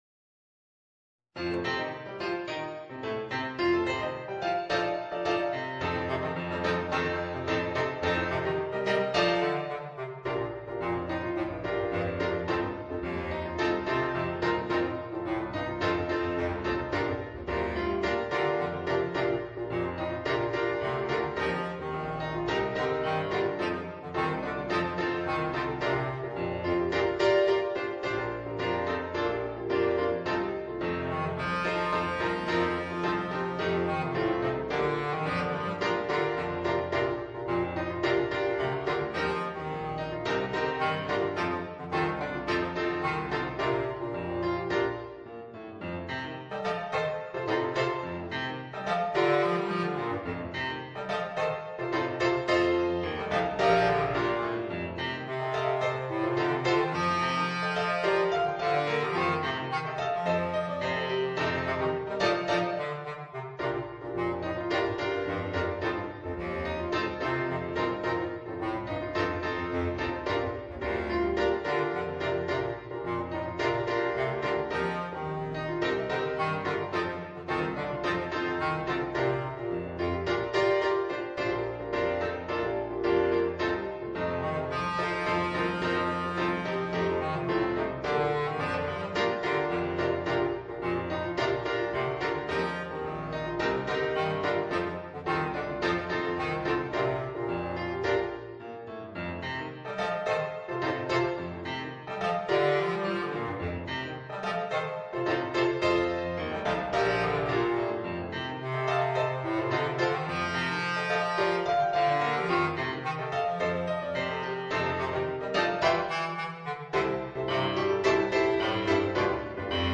Bassklarinette & Klavier